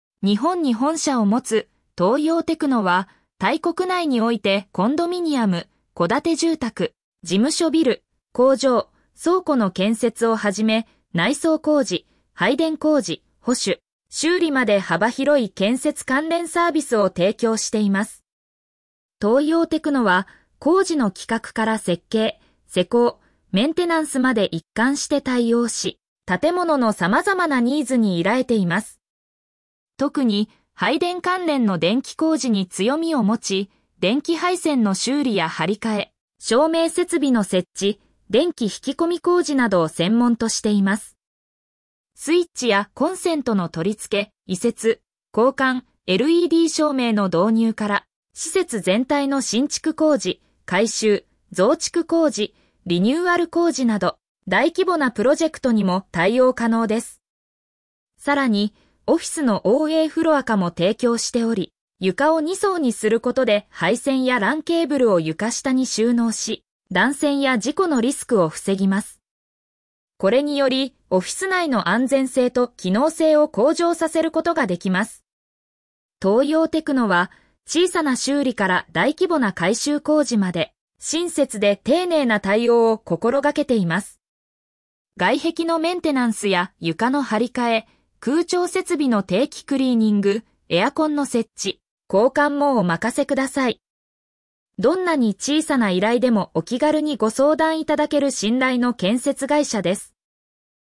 イの建築・内装工事会社、トーヨーテクノアジア タイの建築・内装工事会社、トーヨーテクノアジア イの建築・内装工事会社、トーヨーテクノアジア 読み上げ 日本に本社を持つ「トーヨーテクノ」は、タイ国内においてコンドミニアム、戸建て住宅、事務所ビル、工場、倉庫の建設をはじめ、内装工事、配電工事、保守・修理まで幅広い建設関連サービスを提供しています。